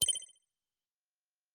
click-project.ogg